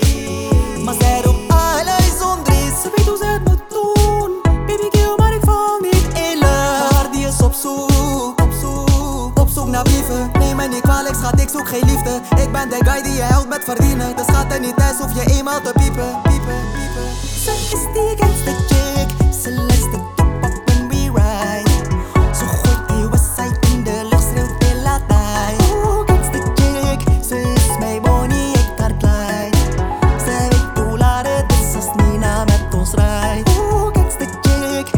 2025-06-20 Жанр: Поп музыка Длительность